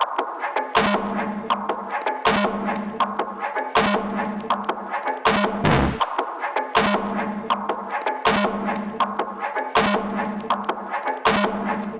Gully-Loops-Dard-Aatma-Drum-Loop-160-BPM.wav